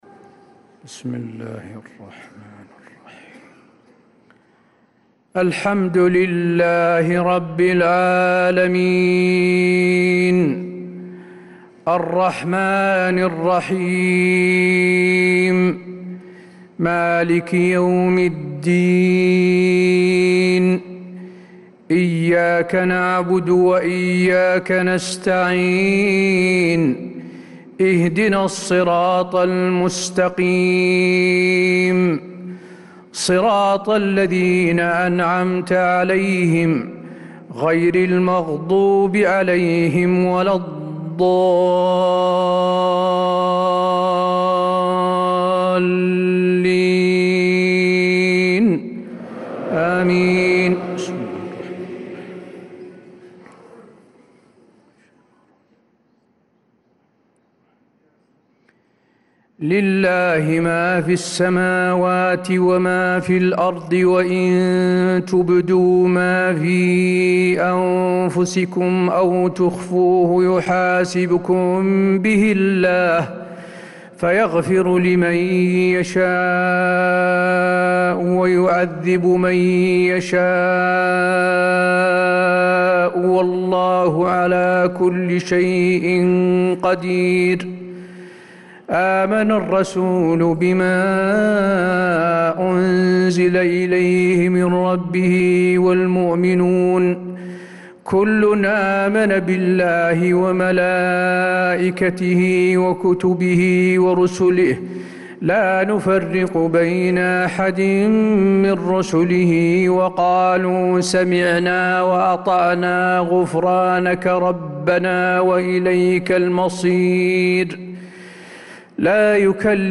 صلاة العشاء للقارئ حسين آل الشيخ 13 رجب 1446 هـ